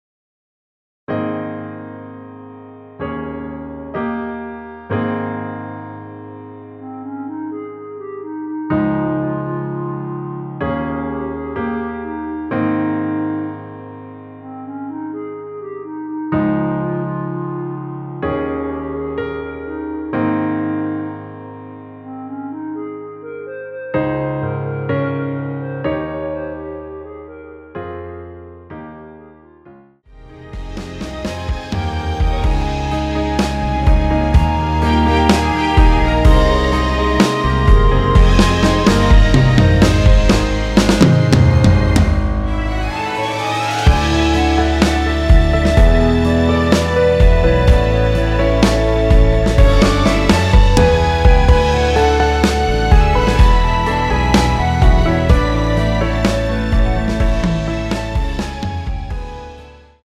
전주 없이 시작하는 곡이라 전주 만들어 놓았습니다.(미리듣기 확인)
원키에서(-1)내린 멜로디 포함된 MR입니다.
Ab
앞부분30초, 뒷부분30초씩 편집해서 올려 드리고 있습니다.